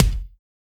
BEAT KICK 02.WAV